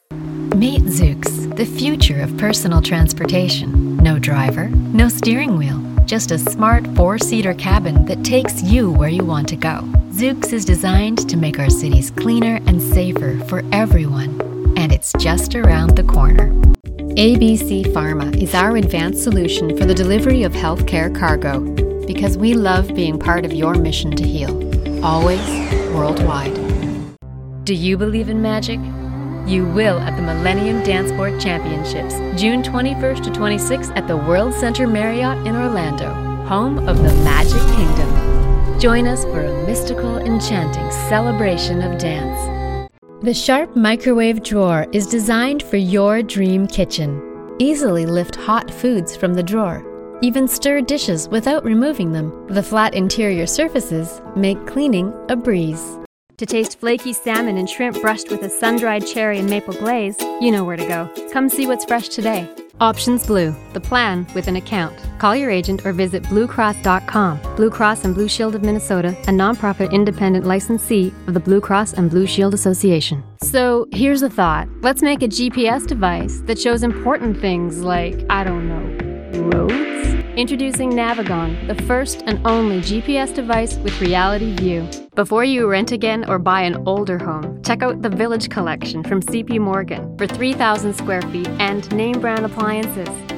Female
English (North American)
Radio Commercials
1229Commercial_Demo.mp3